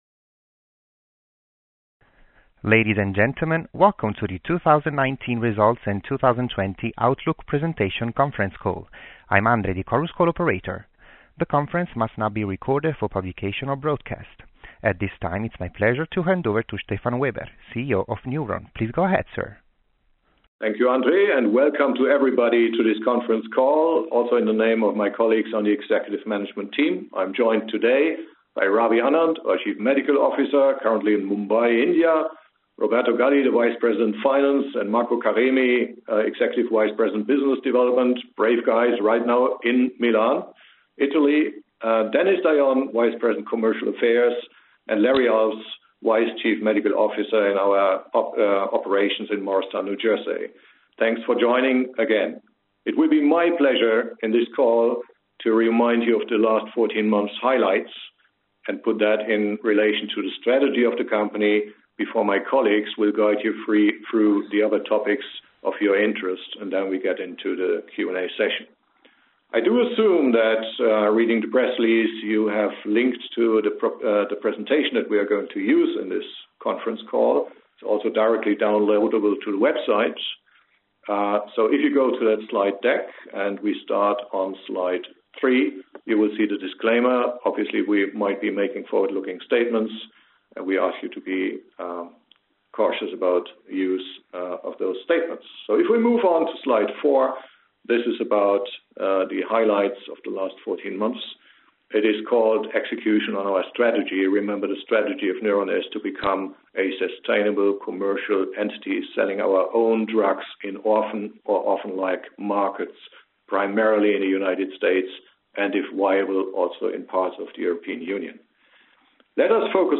Replay of May 28, 2019 investors, analysts and journalists conference call